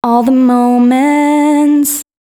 032 female.wav